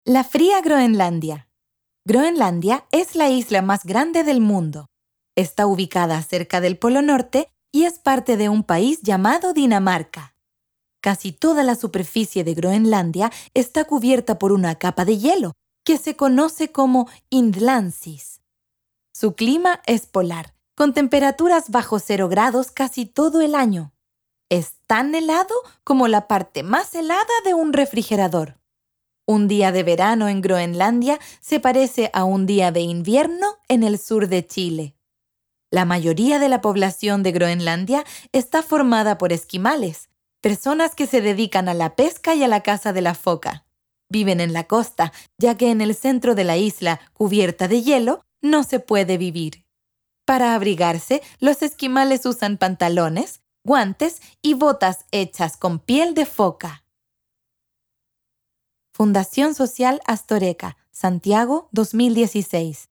Audiocuento